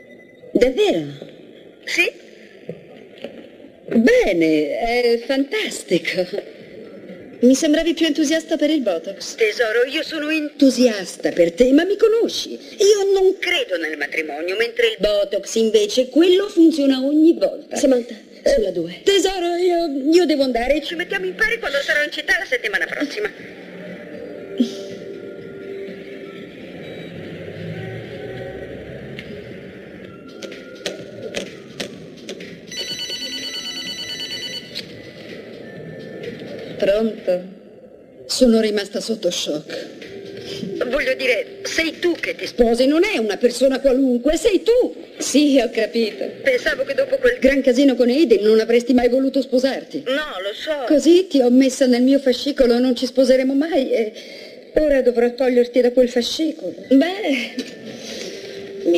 nel film "Sex and the City", in cui doppia Kim Cattrall.